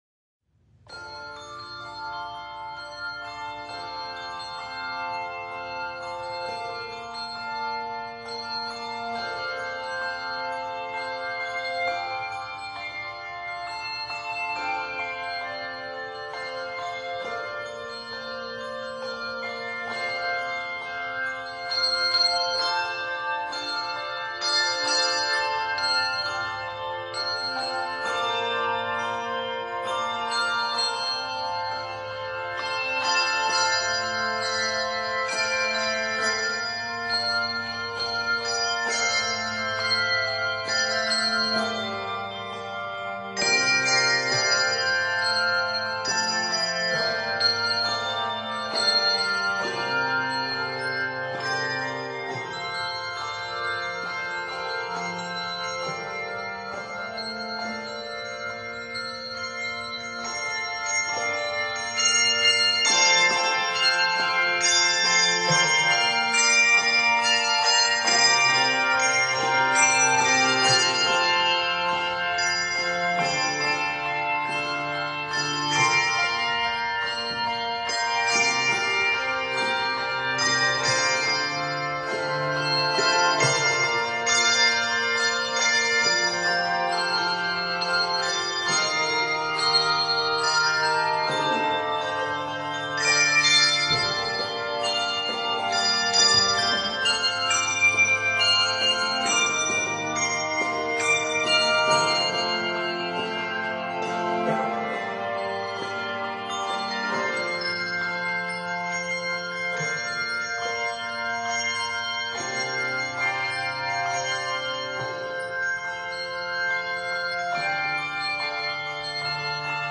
At 54 measures, the F Major title is a fresh new setting.